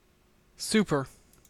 Ääntäminen
US : IPA : /ˈsu.pɚ/
UK : IPA : /ˈs(j)uː.pə(ɹ)/